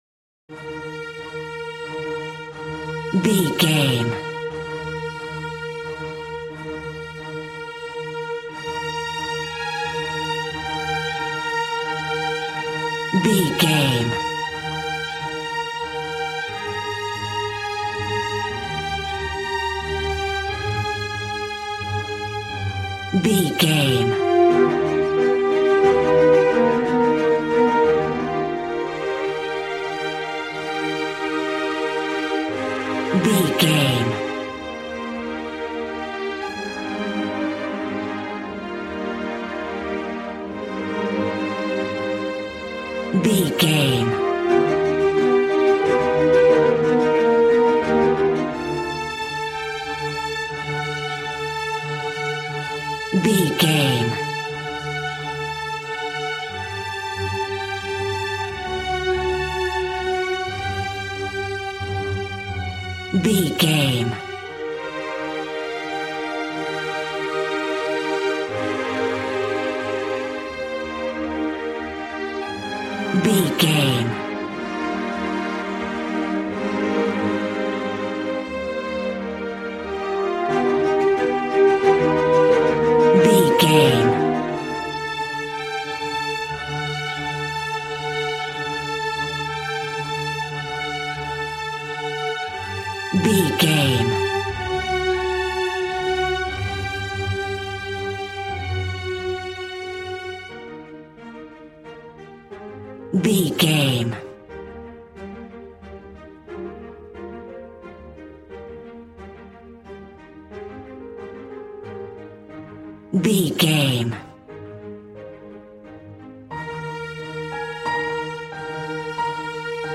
Regal and romantic, a classy piece of classical music.
Aeolian/Minor
regal
cello
violin
strings